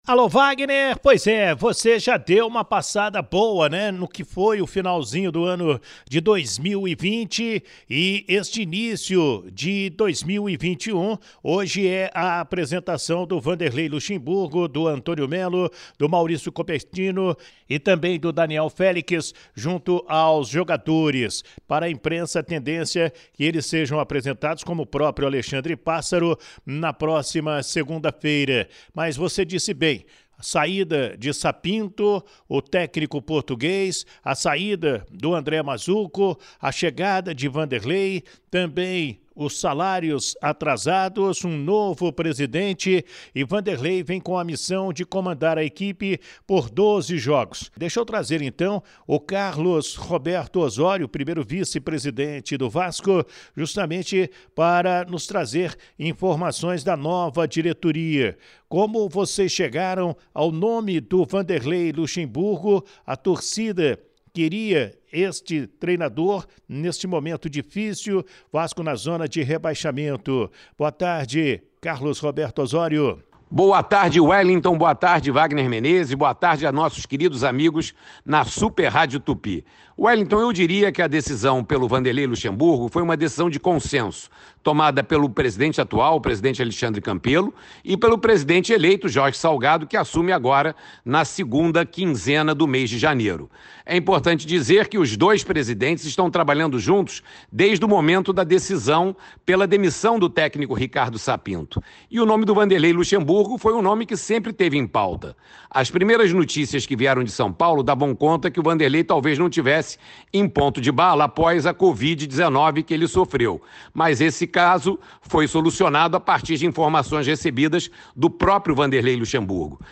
Em entrevista especial para o programa Show da Galera da Super Rádio Tupi deste sábado (02/01)